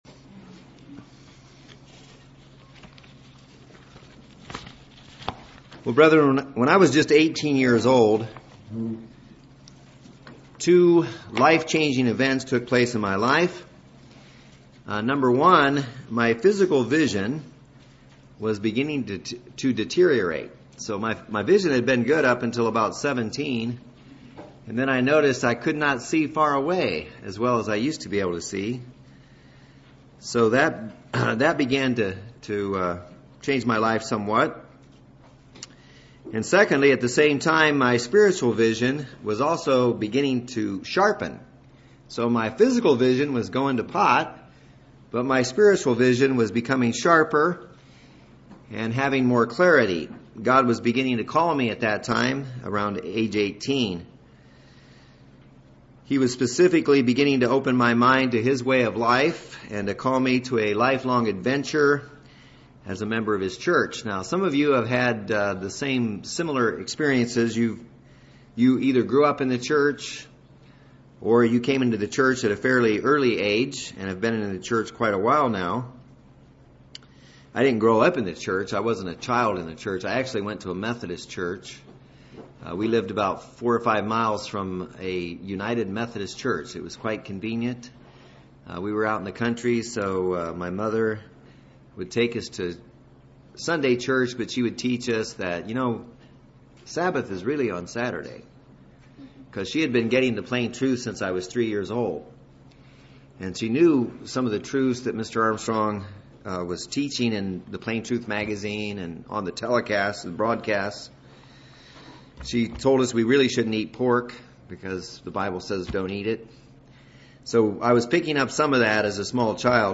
This sermon gives us a reminder to keep our focus on what matters.